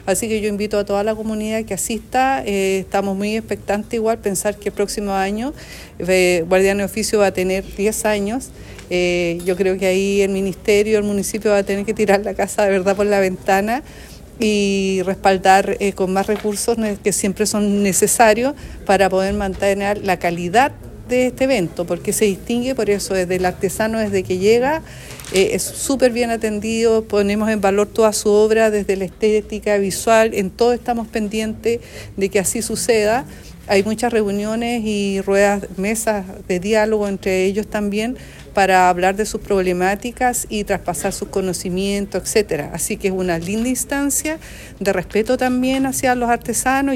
Este lunes, en la sala de sesiones del municipio de Osorno, se realizó el lanzamiento oficial de “Guardianes de Oficios”, una iniciativa que tendrá lugar los días 7, 8 y 9 de noviembre en el Centro Cultural de Osorno.